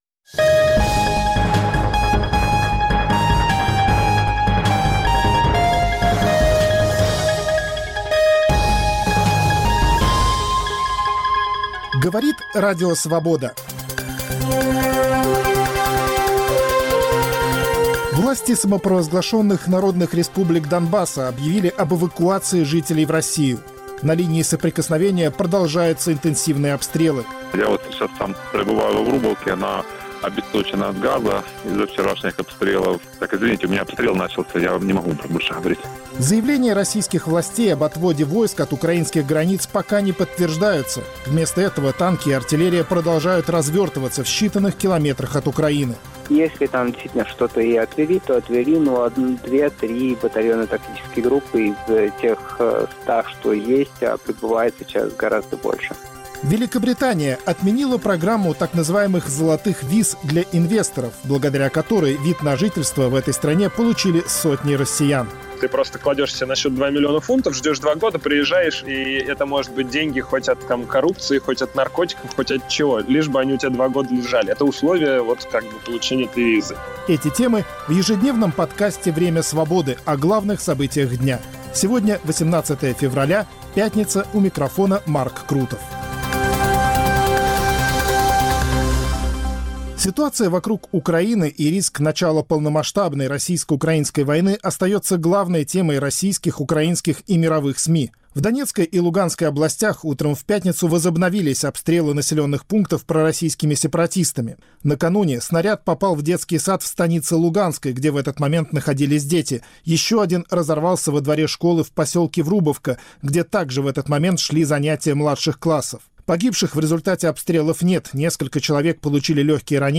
Обострение ситуации в Донбассе: интервью с очевидцами событий. Эксперт расследовательской группы Conflict Intelligence Team: Россия не отводит, а продолжает стягивать войска к украинской границе.